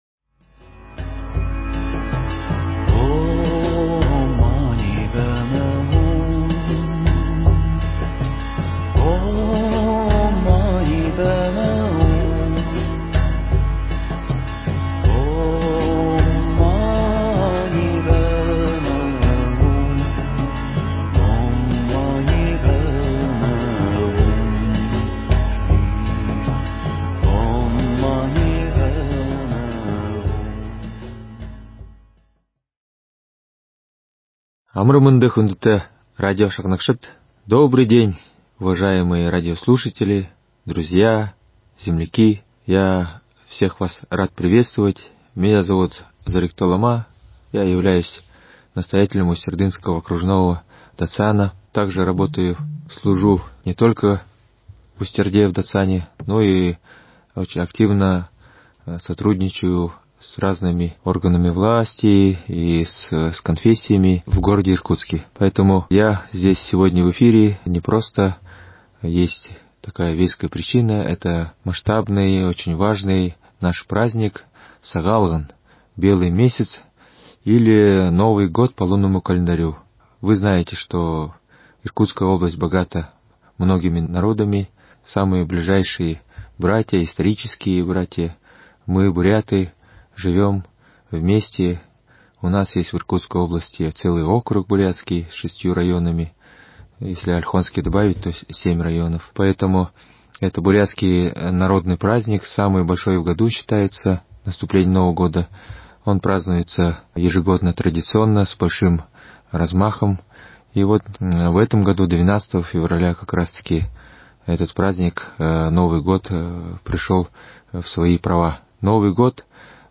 Поздравительное слово ламы в честь праздника Белого месяца – Сагаалгана, который начали отмечать 12 февраля.